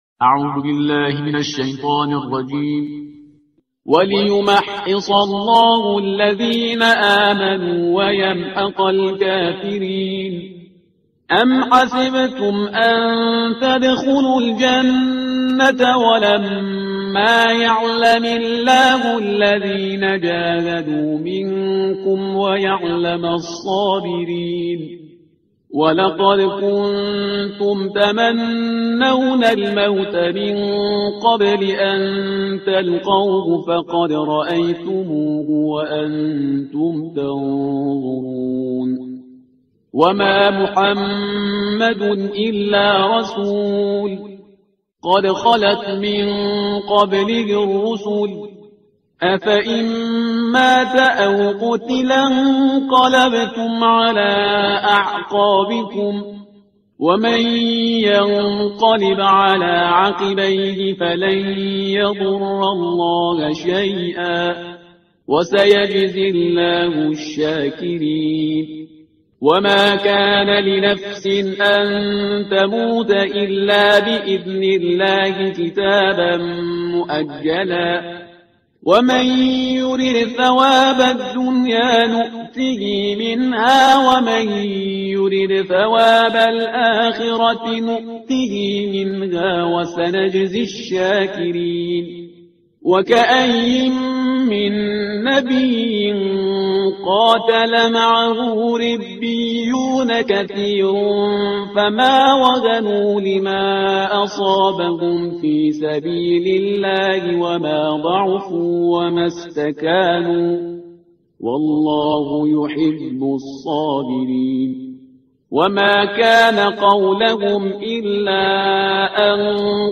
ترتیل صفحه 68 قرآن